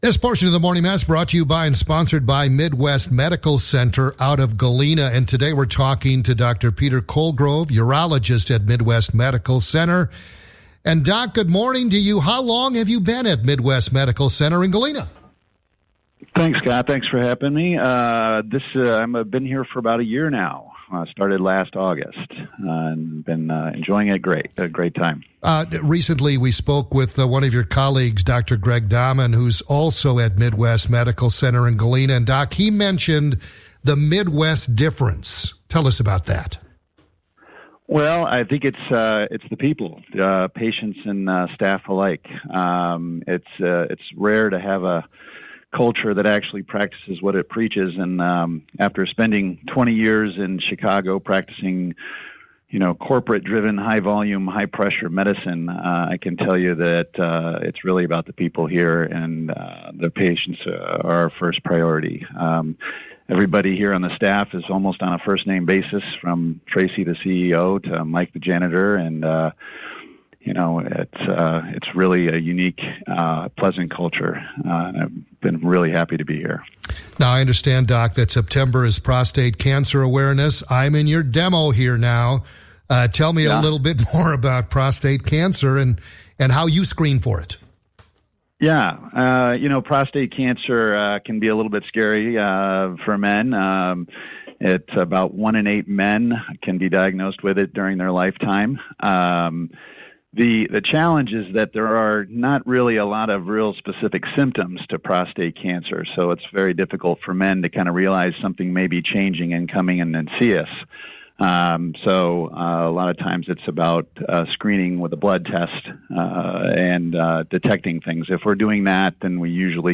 Radio Interview | 9/16/25 Prostate Cancer Awareness Month